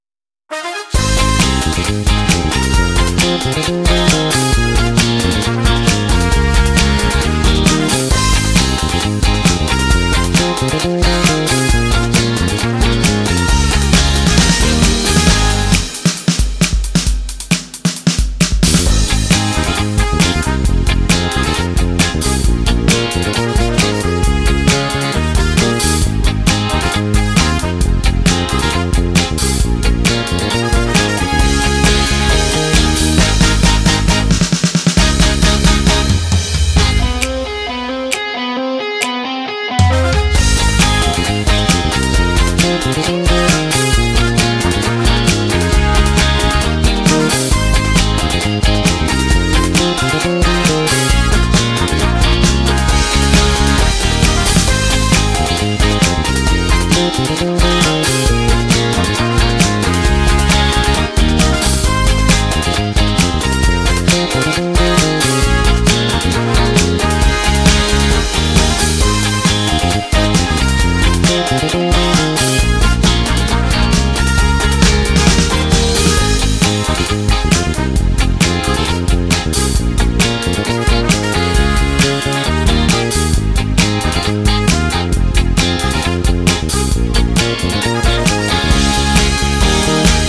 Zonder backing